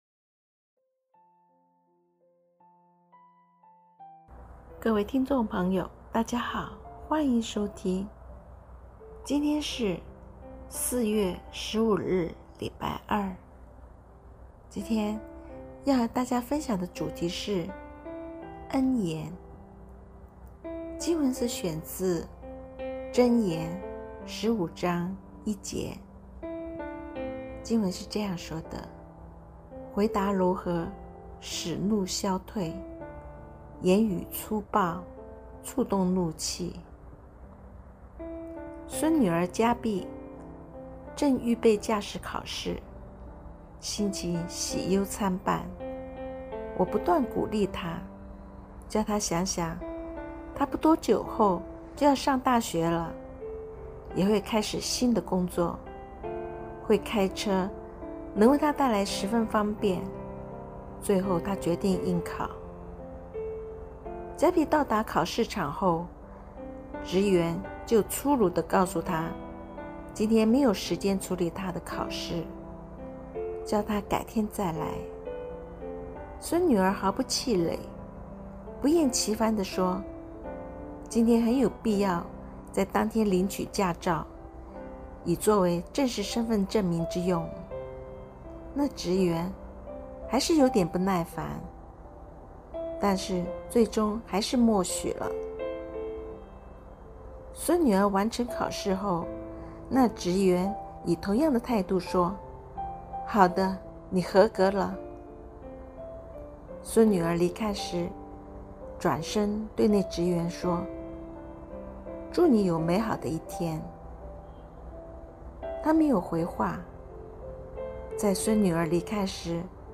恩言(普通話)